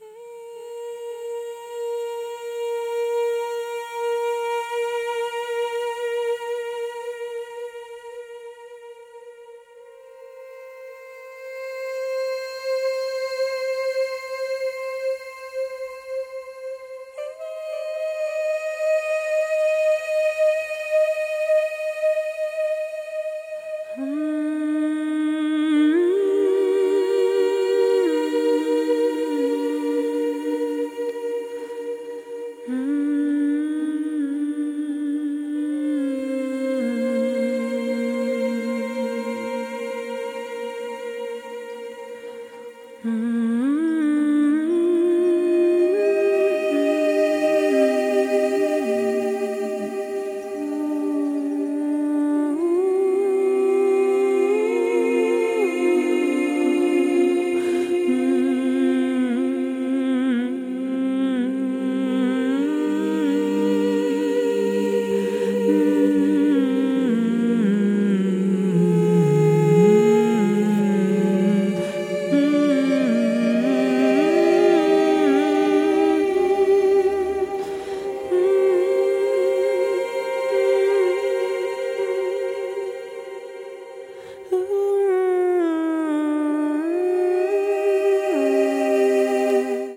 聖歌、イタリアの伝統歌、フルクサス等からインスパイアされた、声によるアンビエント作品！